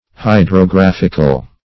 Hydrographic \Hy`dro*graph"ic\, Hydrographical \Hy`dro*graph"ic*al\, a.
hydrographical.mp3